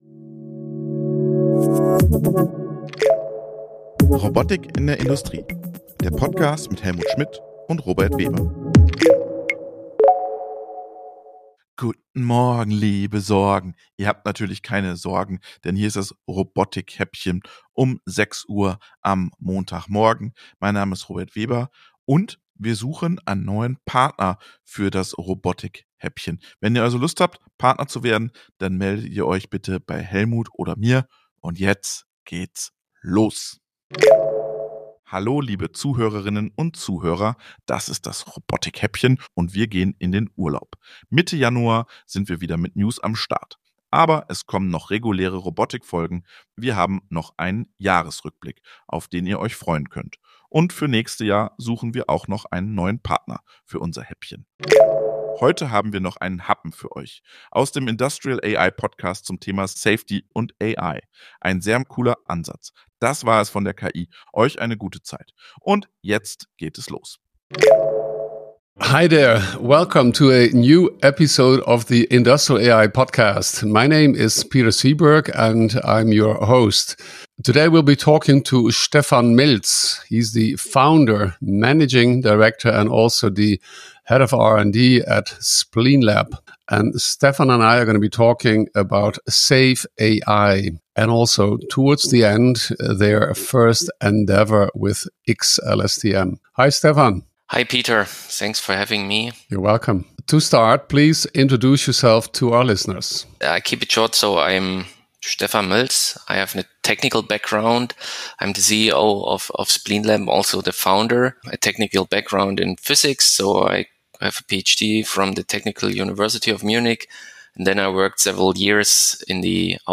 Unser Newshäppchen für die Fahrt zur Arbeit. Immer Montags gibt es die Robotik News - mit-recherchiert, geschrieben und gesprochen mit und von einer KI.